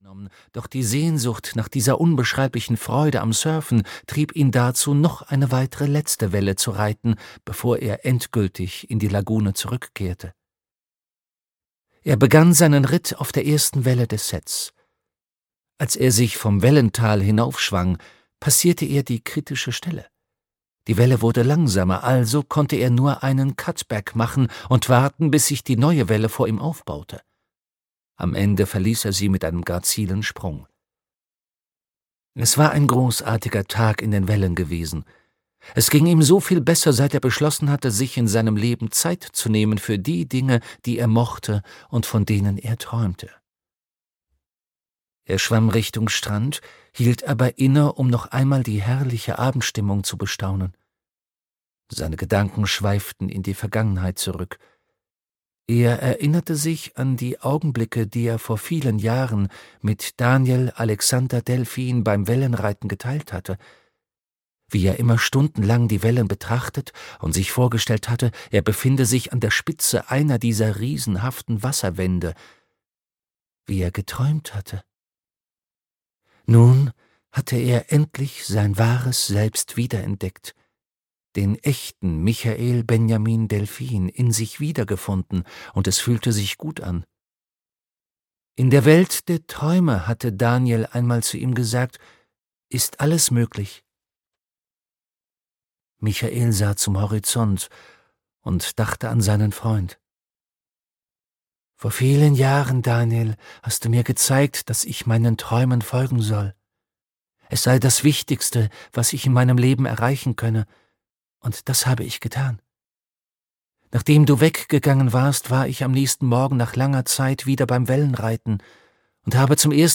Hörbuch Die Heimkehr des träumenden Delphins, Sergio Bambaren.